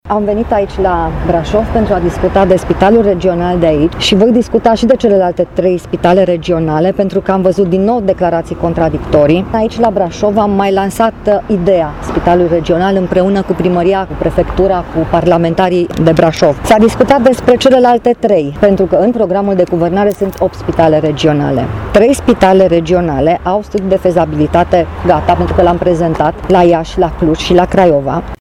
Ministrul Sănătăţii, Sorina Pintea: